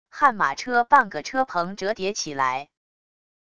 悍马车半个车棚折叠起来wav音频